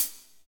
HAT P B CH07.wav